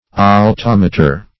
Altometer \Al*tom"e*ter\, n.